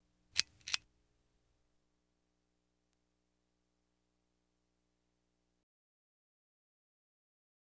Light Switch Pull Type Sound Effect
Download a high-quality light switch pull type sound effect.
light-switch-pull-type.wav